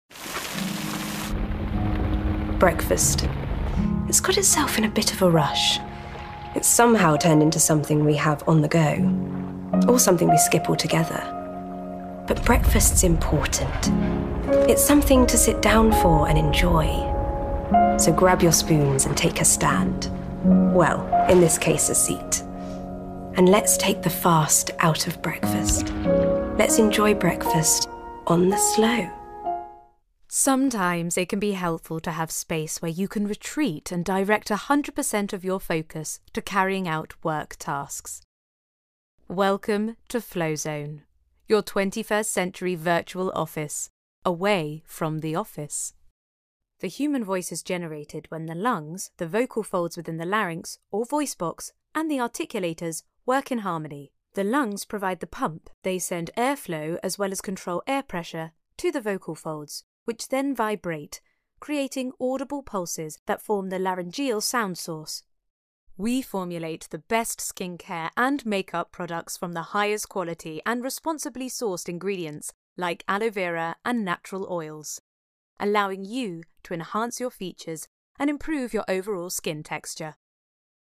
Corporate Reel
• Native Accent: RP
a smooth, reassuring, clear RP commercial voice